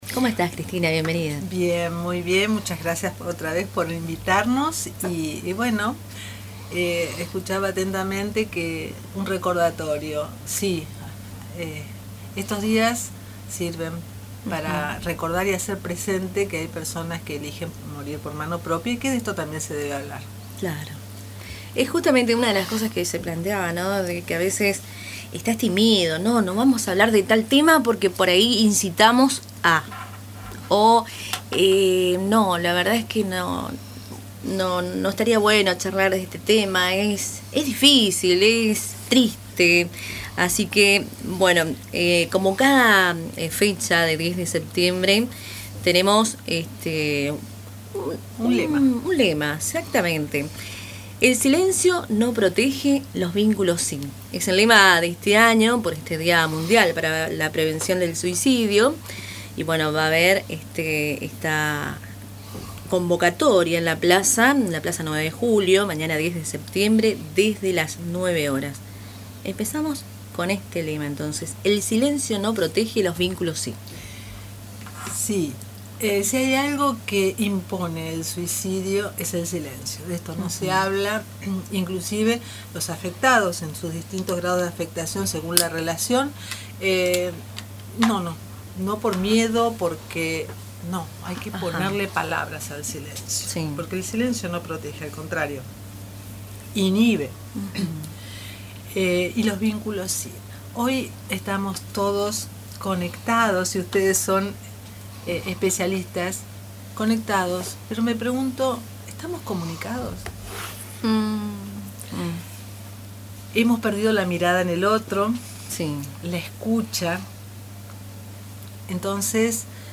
dialogó en Radio Tupambaé y compartió una reflexión sobre la necesidad de hablar del tema, derribar estigmas y fortalecer los vínculos humanos como principal factor protector.